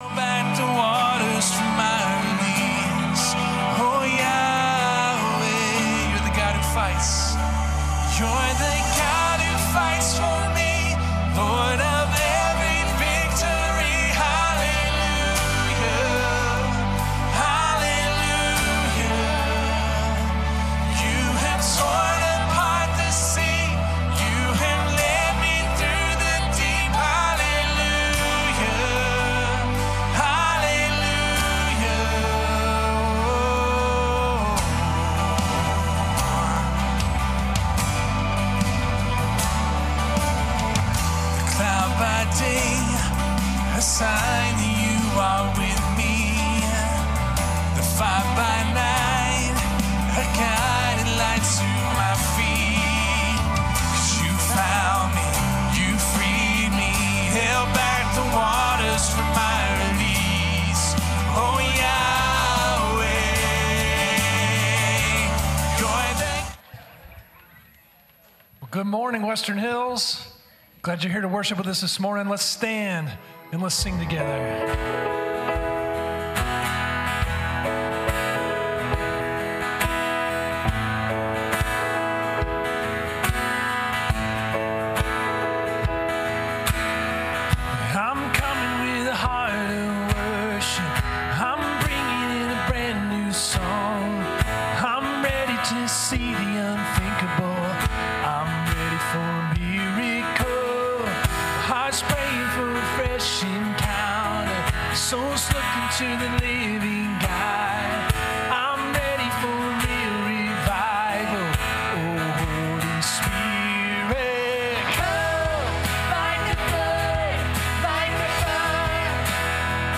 Oct 25, 2021 Loving Like Jesus MP3 Notes Sermons in this Series Loving Like Jesus Reframing Baptism Be Not Afraid…